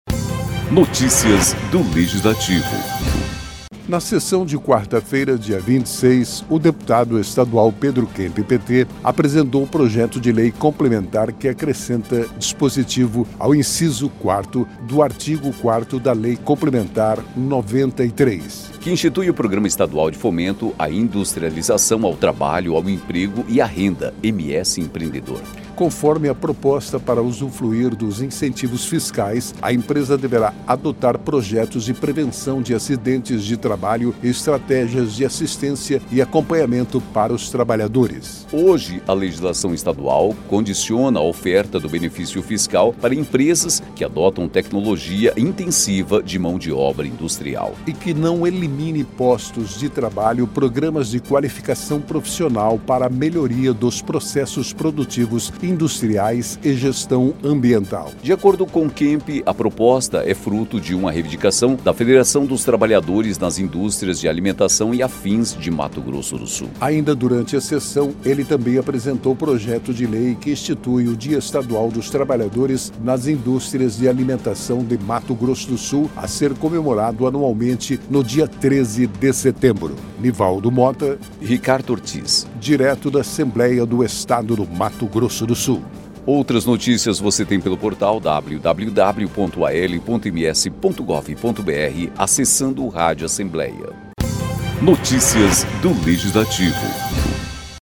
Locução: